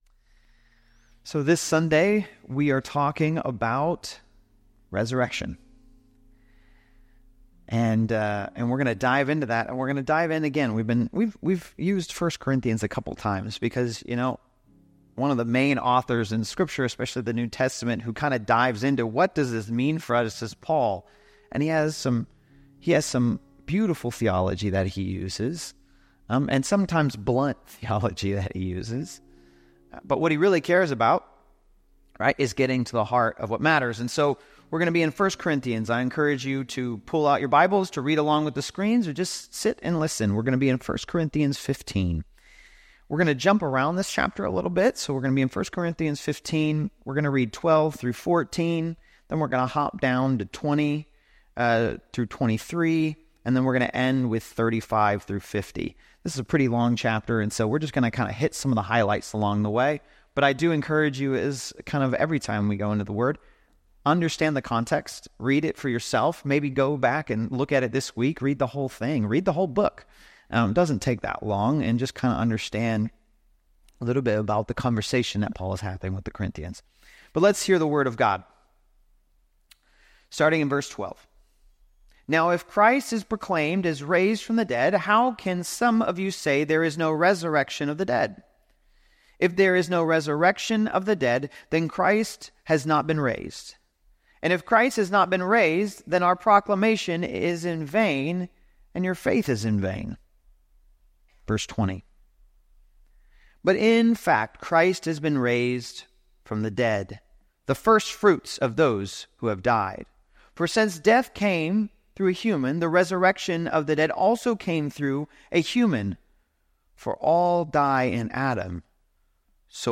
Today’s sermon is about the resurrection of the body, and that’s a tricky subject to answer questions about. Because we can understand what the resurrection of the body meant then – but what does it mean to us today?